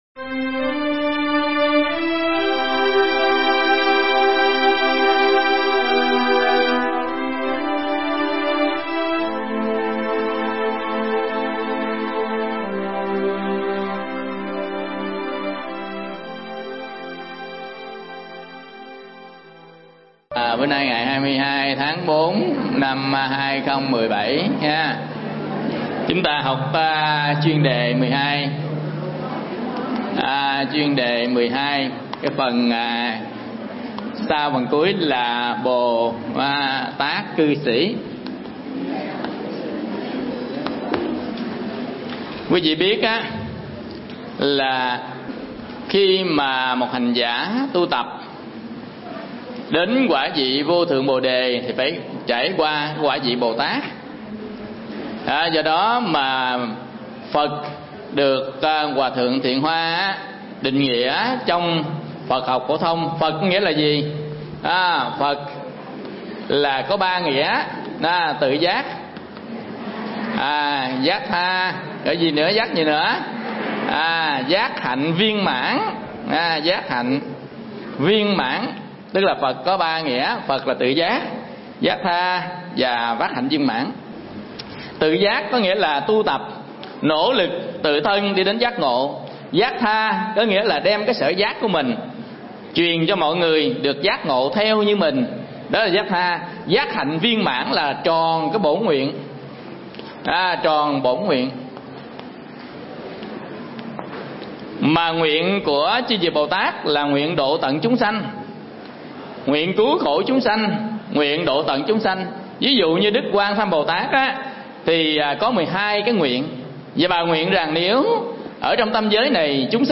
Thuyết pháp Bồ Tát Cư Sĩ